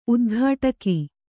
♪ udghāṭaki